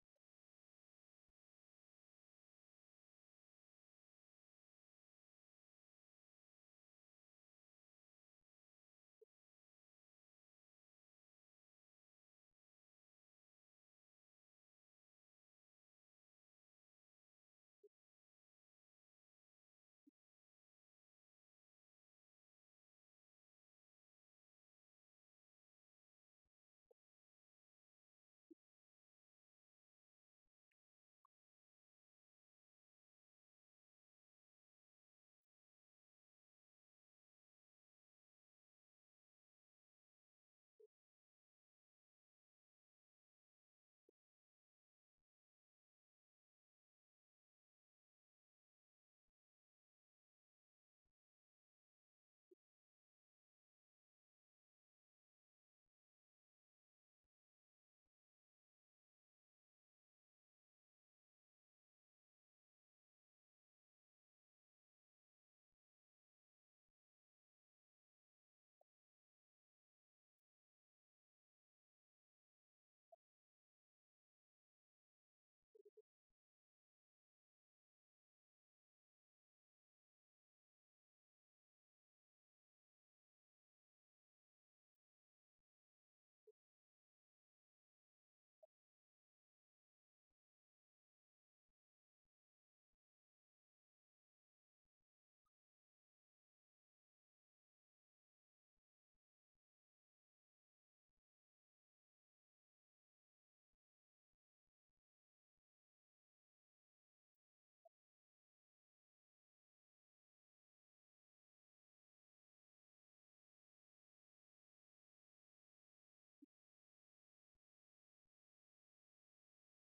musique, ensemble musical
repiques de 78t
Pièce musicale inédite